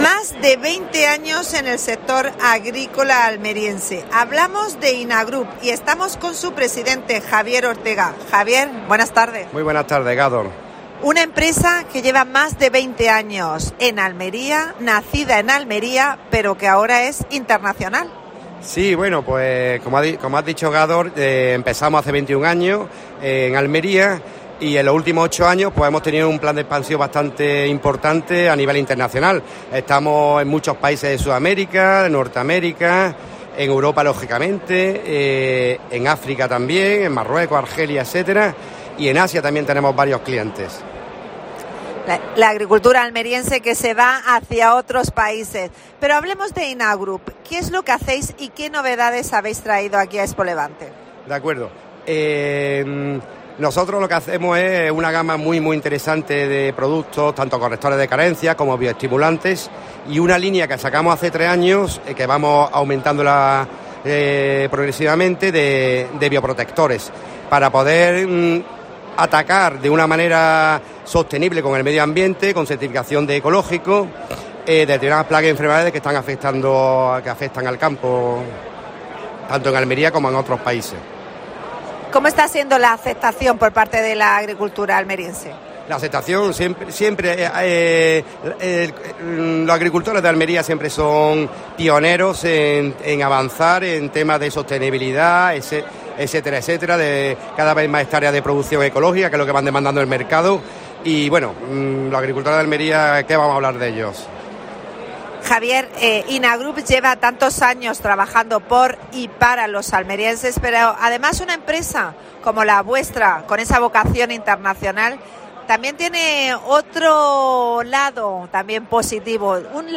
AUDIO: Especial ExpoLevante.